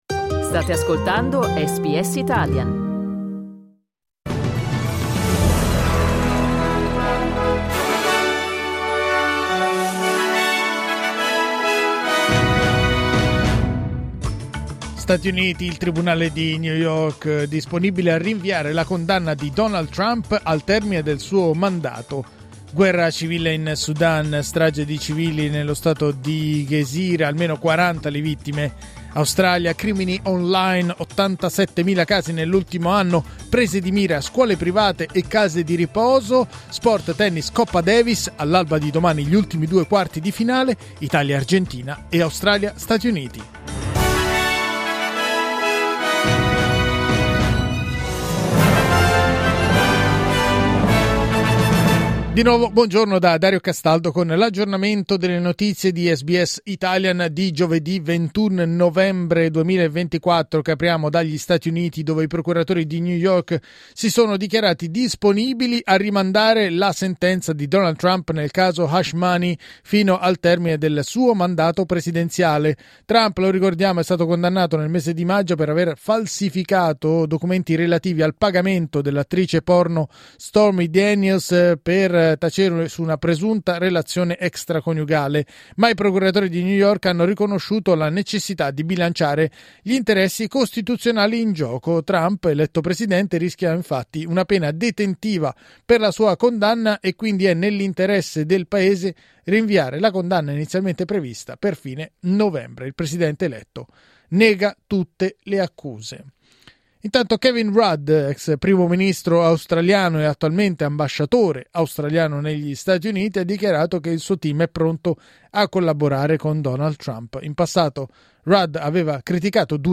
News flash giovedì 21 novembre 2024